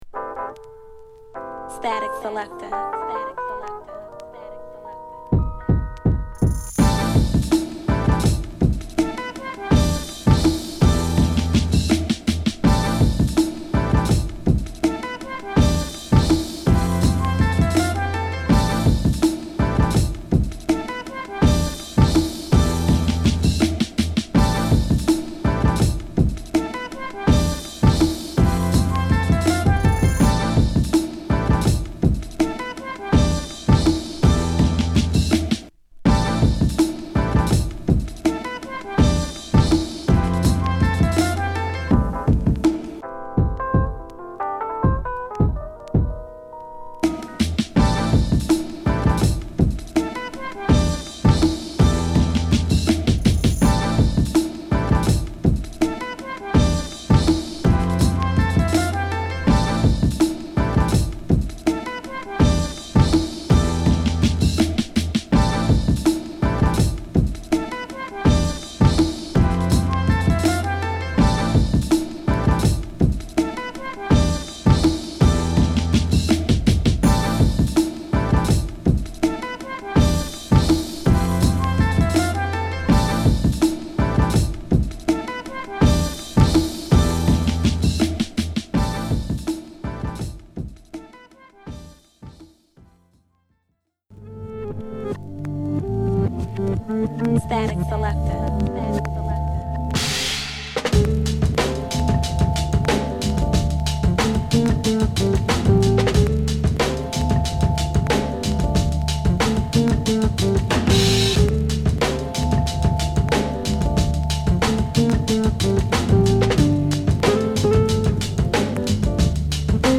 • B4 Central Bookings (Instrumental)